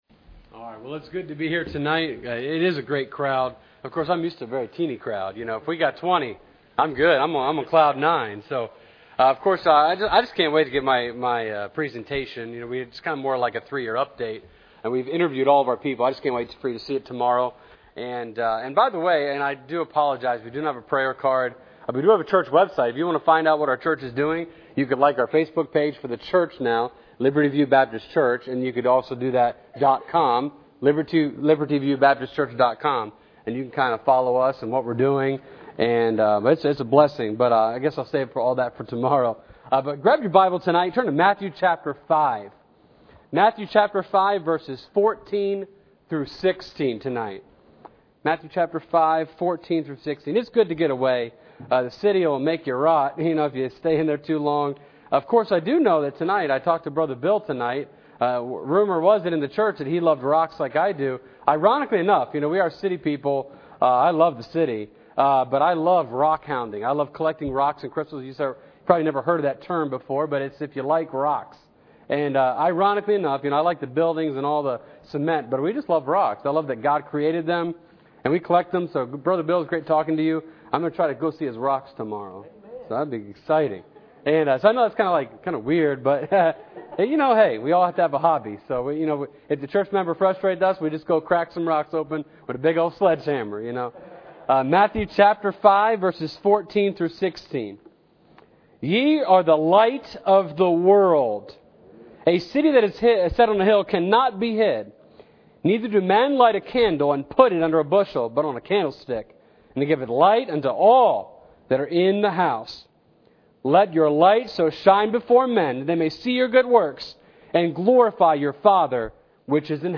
Series: 2017 Missions Conference
Service Type: Special Service